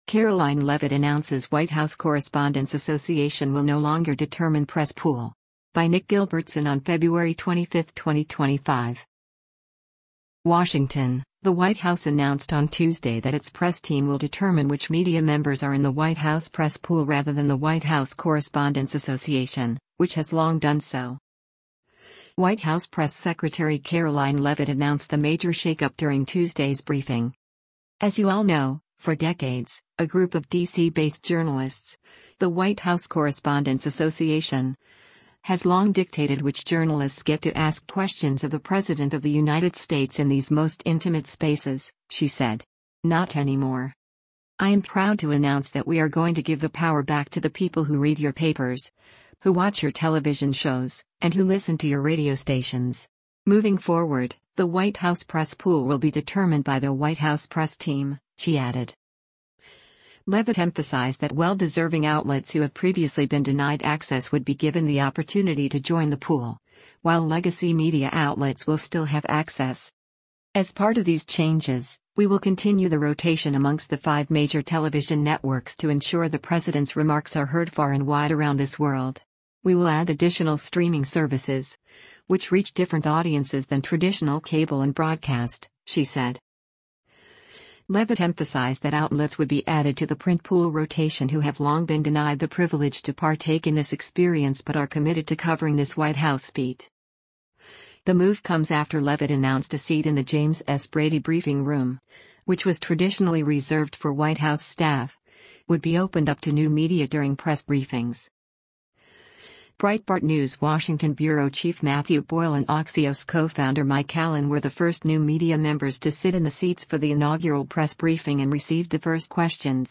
White House press secretary Karoline Leavitt announced the major shake-up during Tuesday’s briefing.